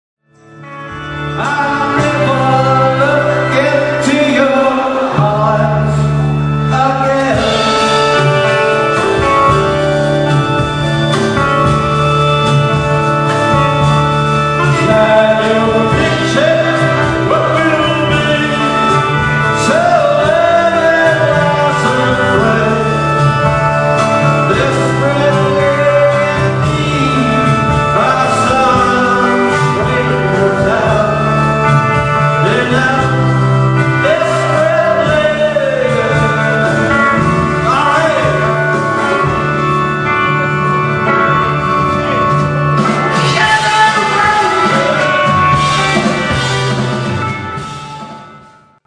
(vocals)
(organ, bass, backing vocals)
(guitar)
(drums)
absolutely live mp3-Soundfiles